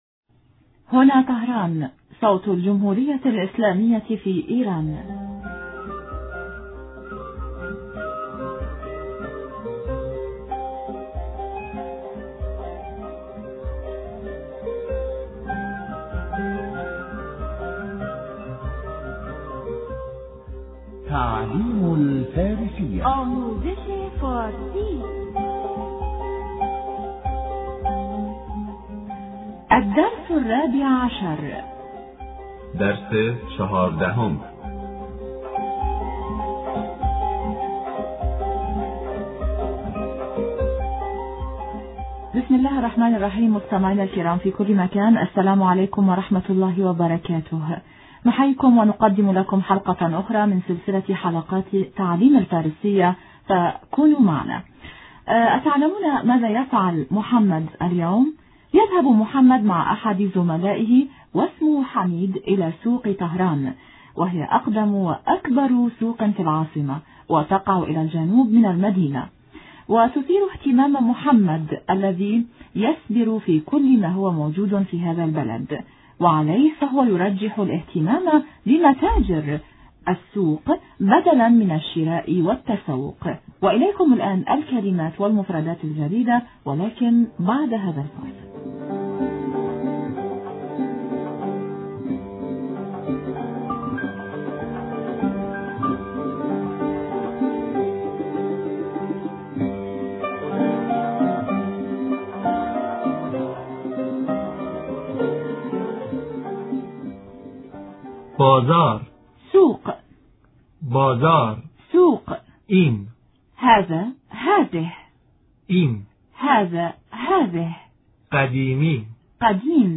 نستمع الآن إلى الحوار بين محمد وزميله حميد: